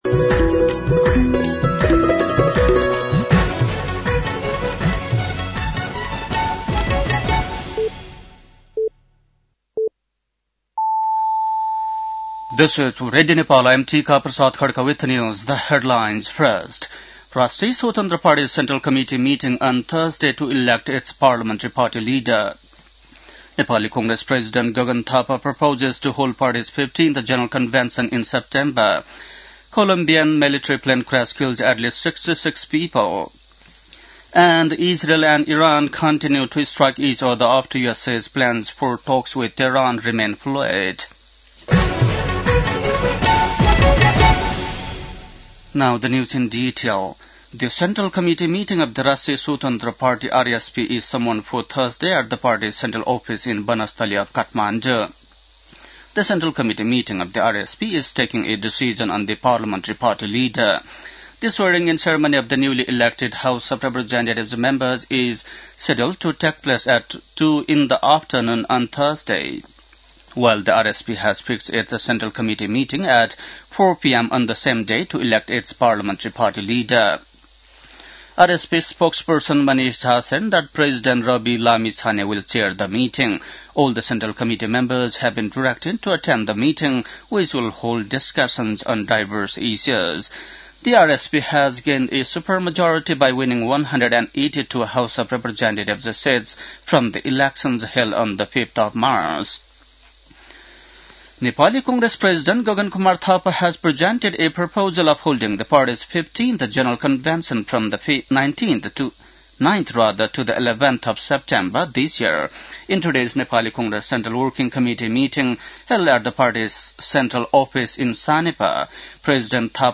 बेलुकी ८ बजेको अङ्ग्रेजी समाचार : १० चैत , २०८२